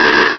pokeemerald / sound / direct_sound_samples / cries / slakoth.aif
slakoth.aif